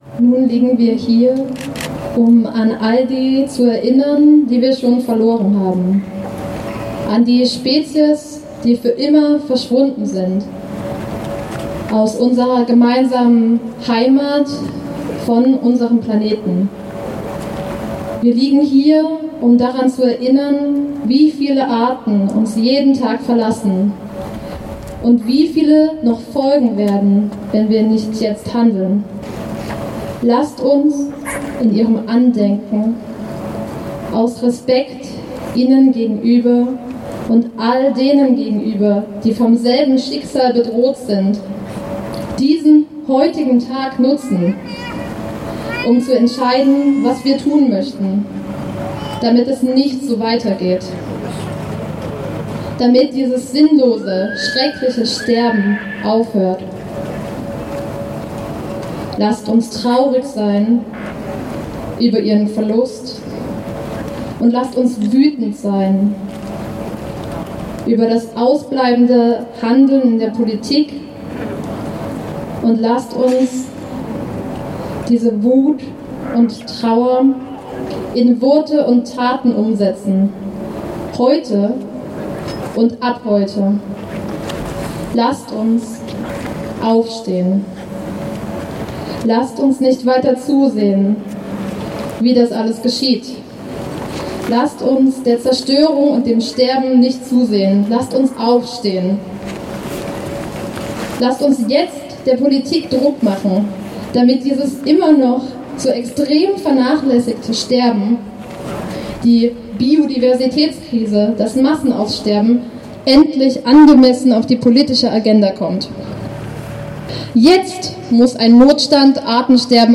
Abschlusskundgebung
Die Motivationsrede zum „Die-in“ (Teil 2) (Audio 11/15) [MP3]